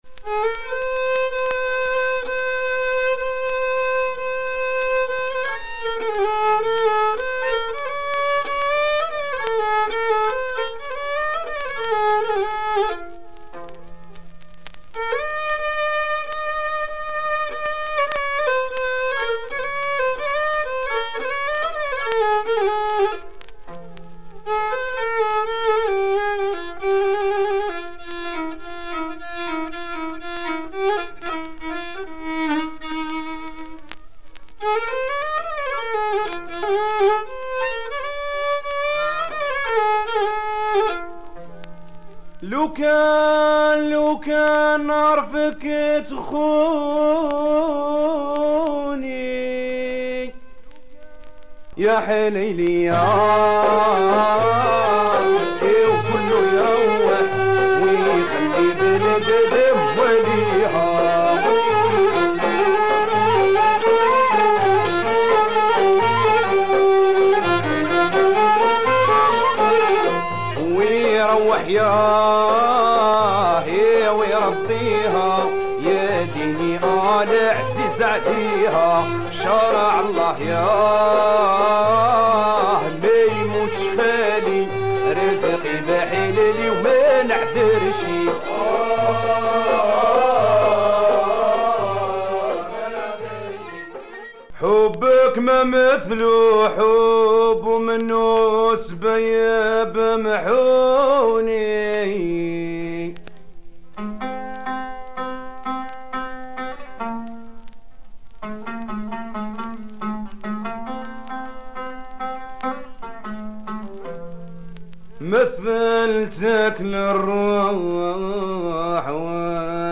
Rare proto rai 7' pressed in Algeria.